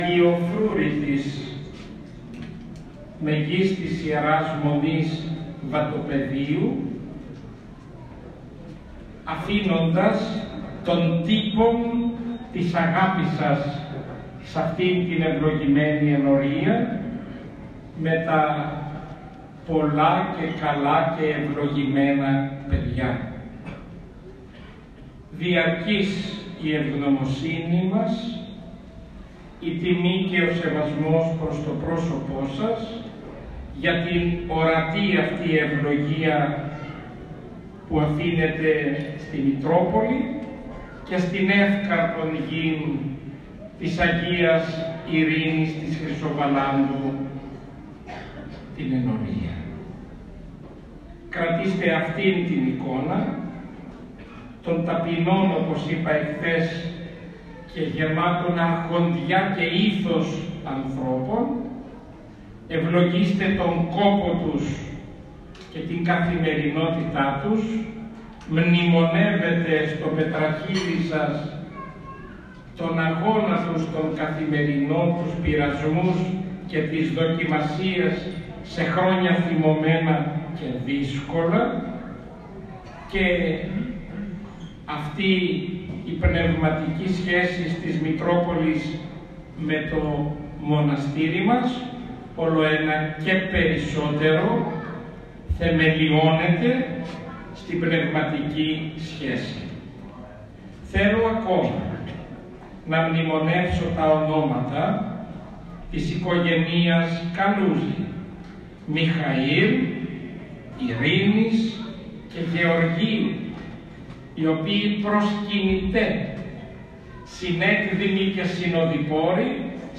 Πανηγυρική Αρχιερατική θεία λειτουργία τελέστηκε σήμερα στον Ιερό Ναό Αγίας Ειρήνης Χρυσοβαλάντου στην Ν. Ευκαρπία Θεσσαλονίκης.
Ακούστε την ομιλία του Σεβασμιωτάτου Μητροπολίτη Νεαπόλεως και Σταυρουπόλεως κ. Βαρνάβα:
Ομιλία-νεαπολεως.m4a